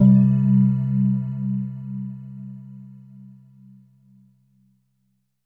LEAD C2.wav